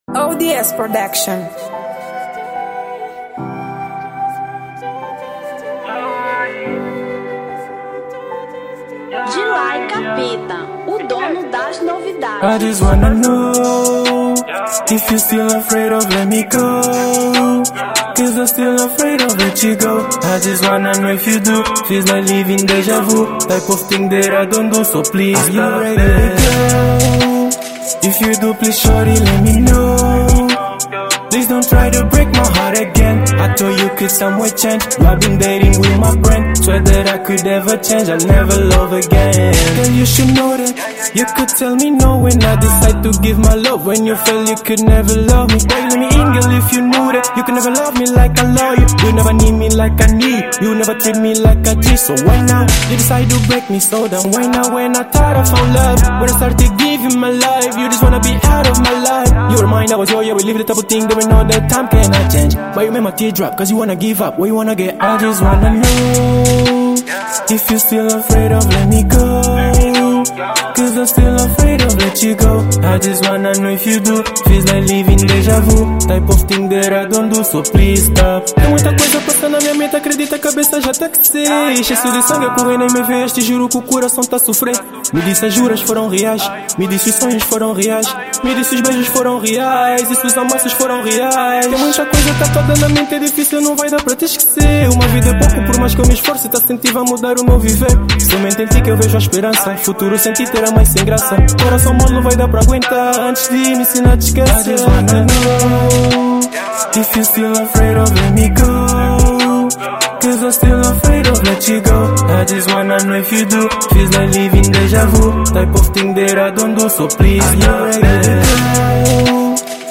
Drill 2025